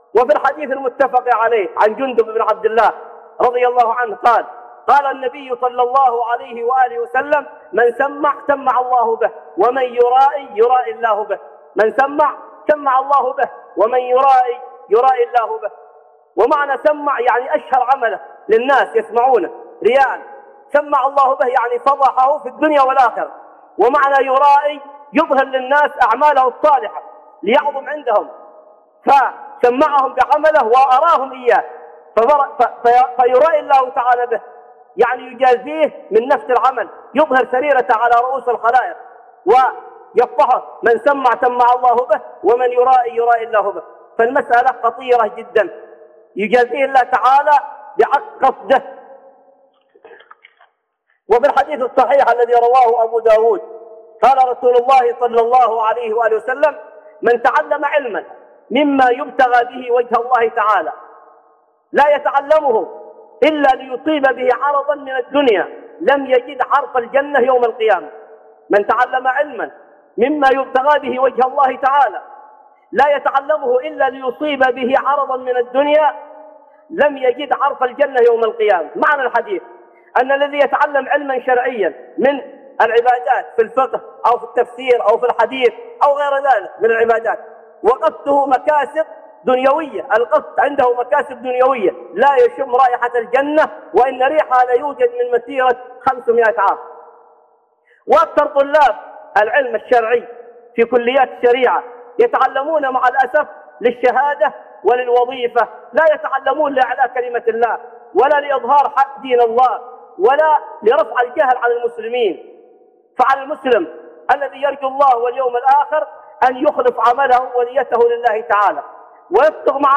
من راءى راءى الله به ومن سمع سمع الله به - خطب